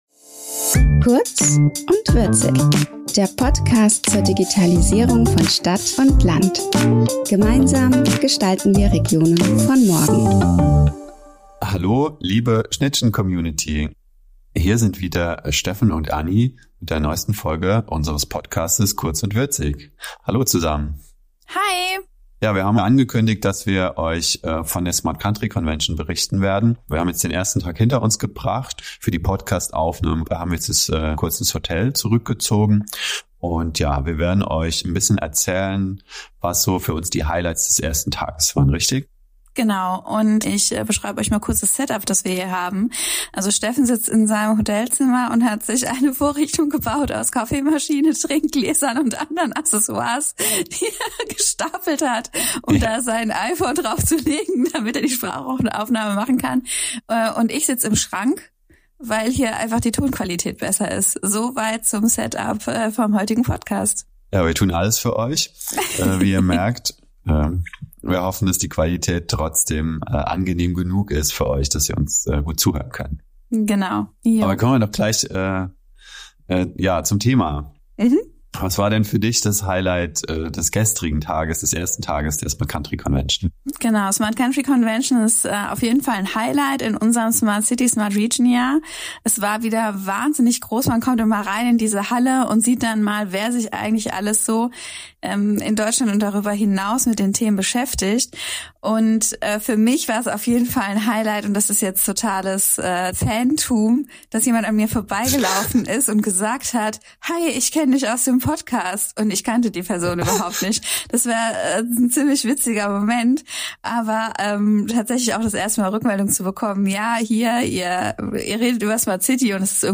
Live aus dem Kleiderschrank im Hotel.